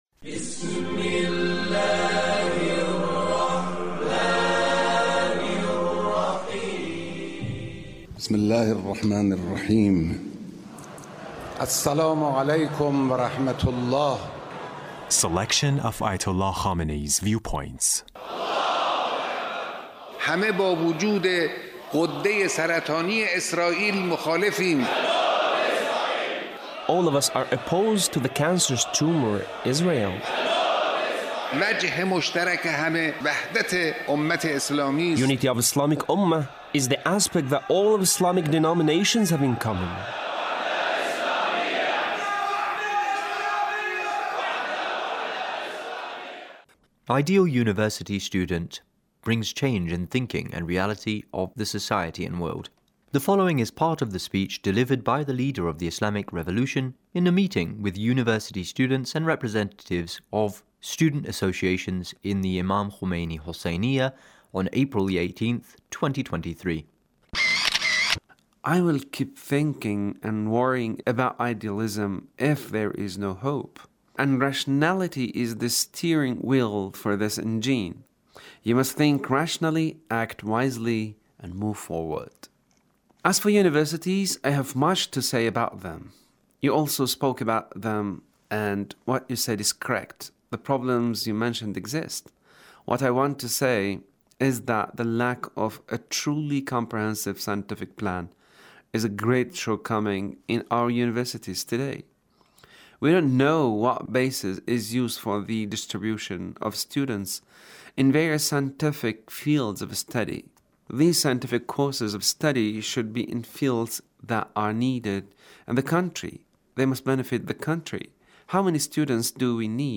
Leader's Speech (1718)
Leader's Speech with University Student